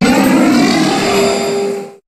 Cri de Solgaleo dans Pokémon HOME.